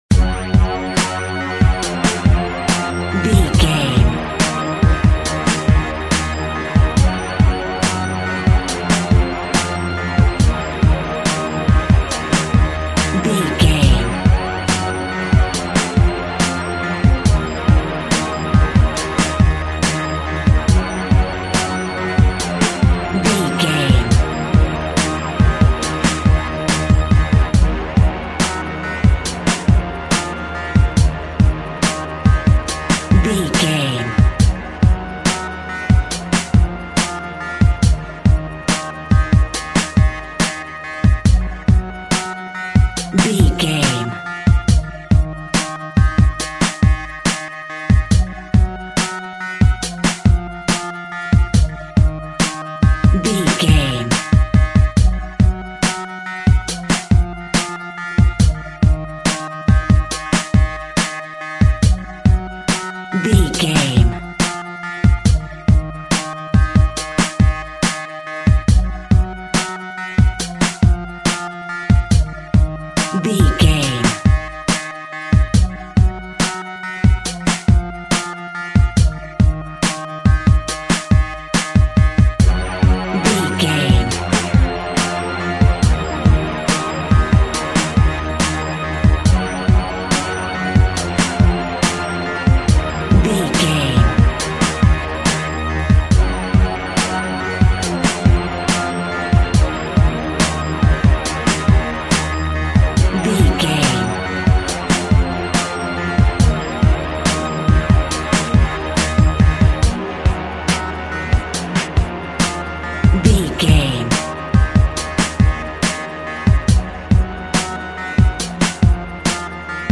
Dark Rap.
Aeolian/Minor
E♭
electronic
drum machine
synths